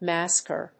発音
• / ˈmæskɝ(米国英語)
• / ˈmæskɜ:(英国英語)